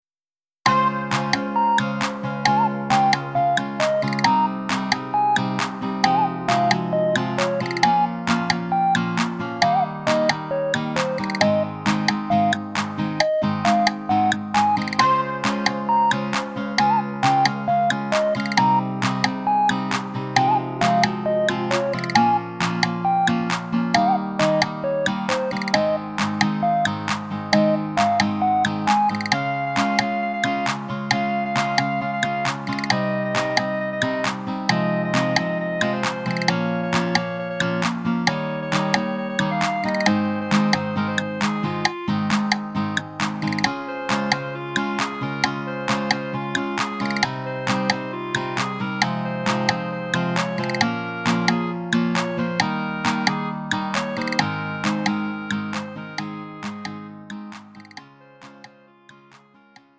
음정 -1키 3:23
장르 가요 구분 Lite MR